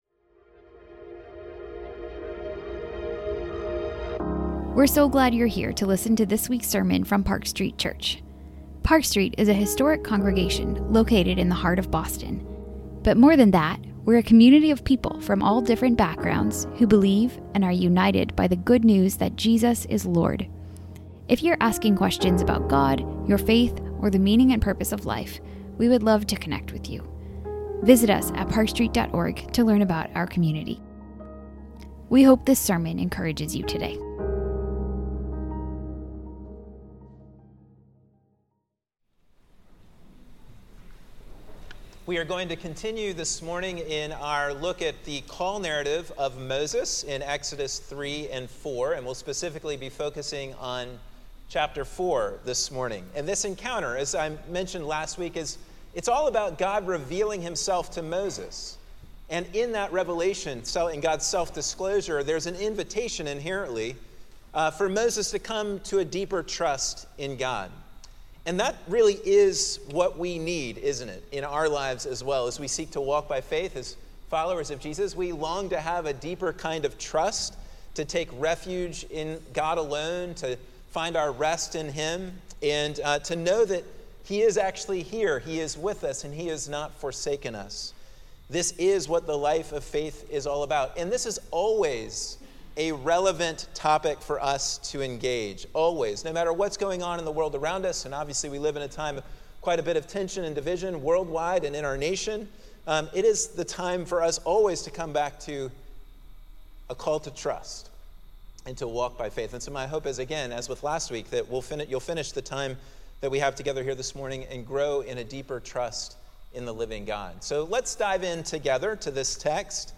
Park Street Church Sermons Podcast - The God Who Provides | Free Listening on Podbean App
This sermon continues to explore Moses' encounter with God at the burning bush. As Moses interacts and wrestles further, God reveals more about his provision for Moses and calls him to trust him and to go on the mission.